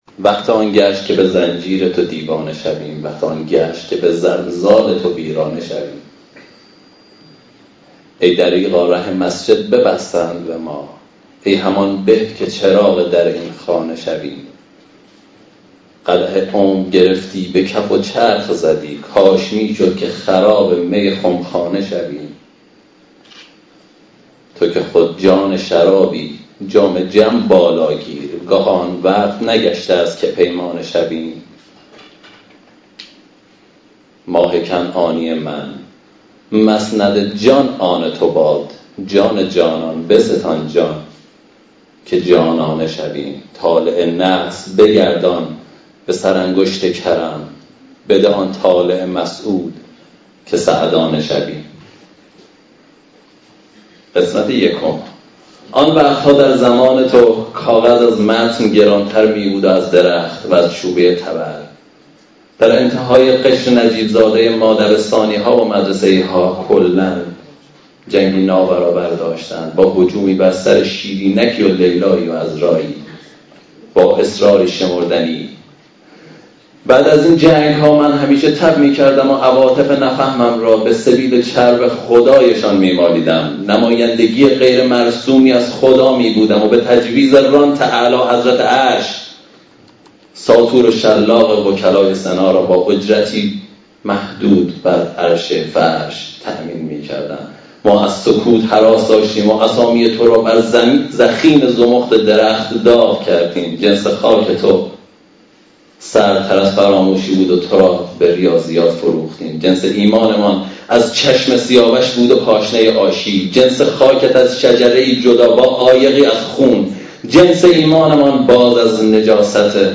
به حول و قوه ی الهی و عنایت حضرت خدیجه علیها السلام، روز جمعه هفدهم اردیبهشت ماه 1395، شانزدهمین جلسه ی شعر بداهه برگزار شد.